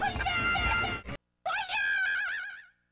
Another sound I really loved was the HOOYAAAAA~A~A~A~a~a~ahhhh~ when you lift the weight in "Lift and Shout". But the new version they have doesn't sound nearly as great. I made a comparison down below.